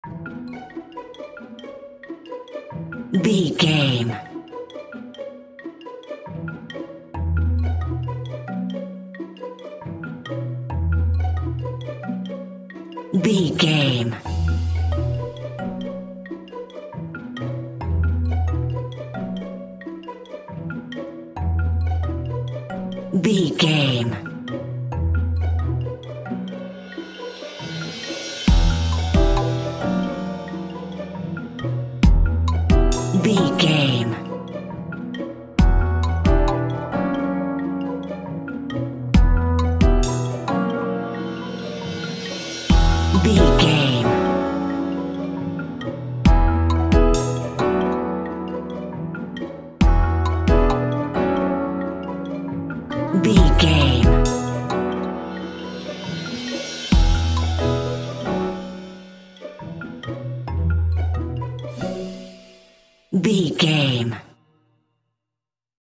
Mixolydian
percussion
strings
double bass
synthesiser
silly
circus
goofy
comical
cheerful
perky
Light hearted
quirky